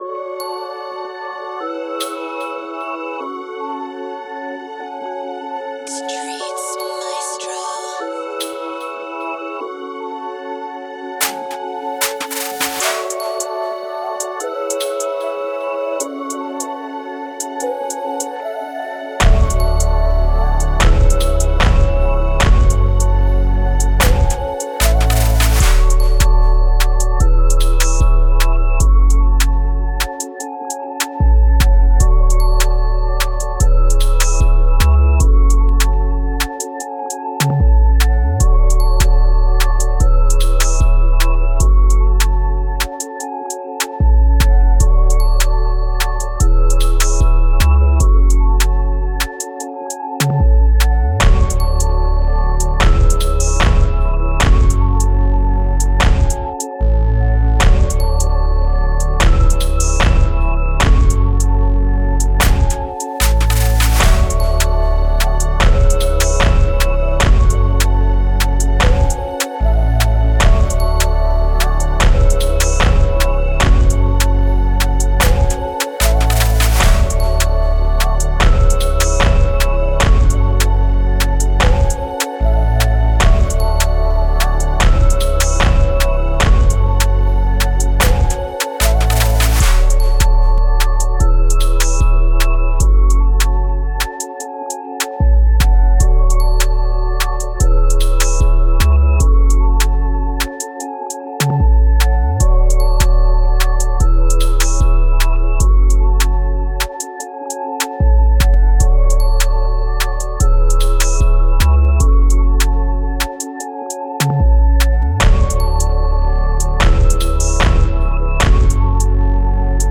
Moods: laid back, intimate, mellow
Genre: Sexy Drill
Tempo: 150
BPM 130